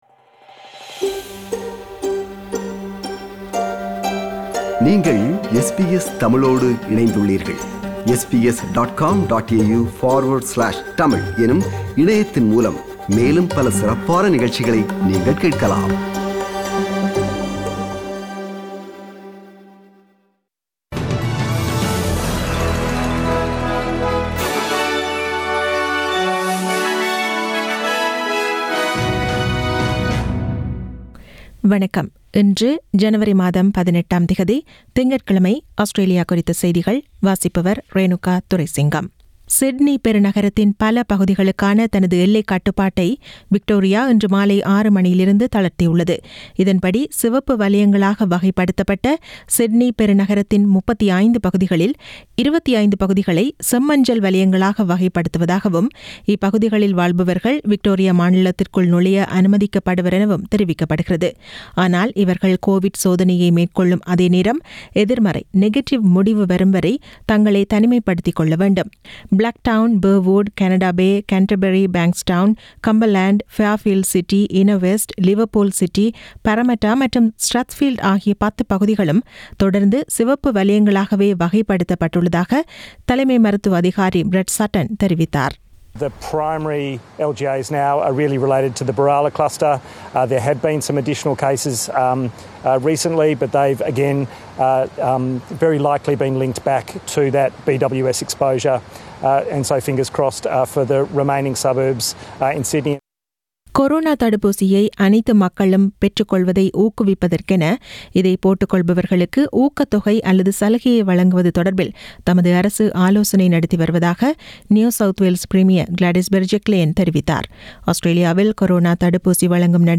Australian news bulletin for Monday 18 January 2021.